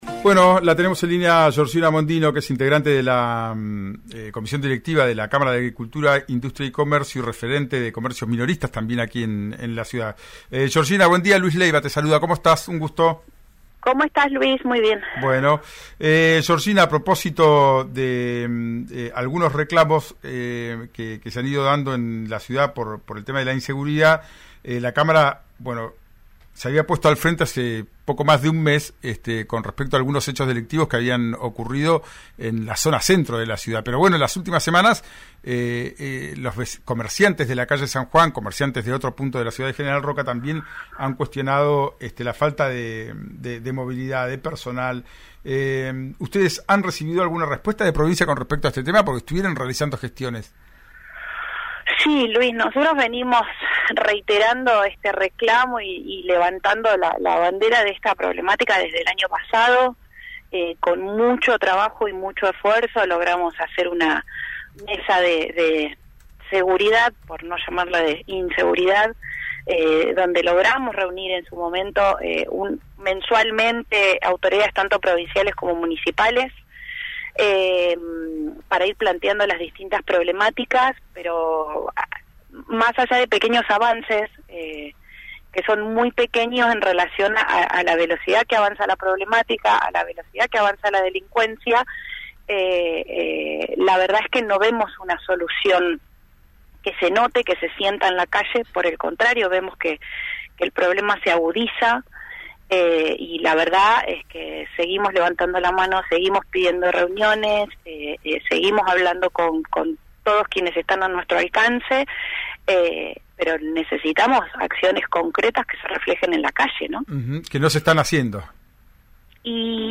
En diálogo con el programa radial «Ya es tiempo»